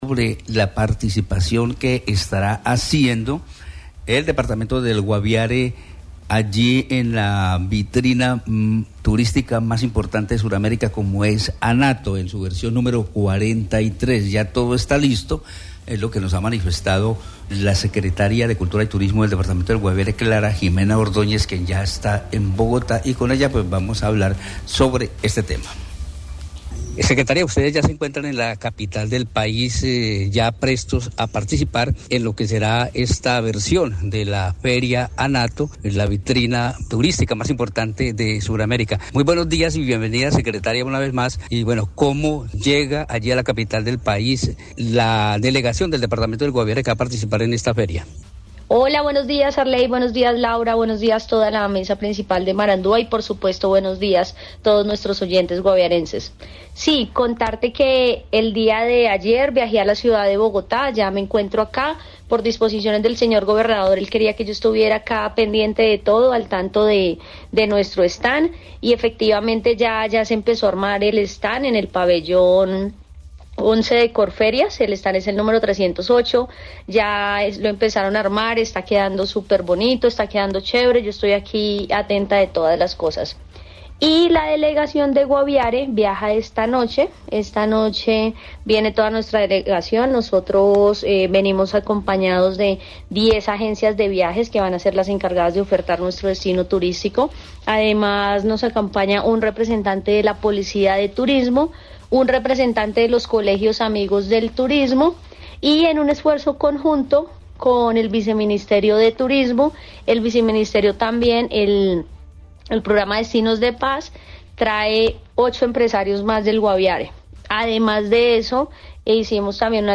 Marandua Noticias conversó con Clara Ximena Ordoñez, secretaria de Cultura y Turismo del Guaviare, quien detalló la preparación de la delegación del departamento para este evento.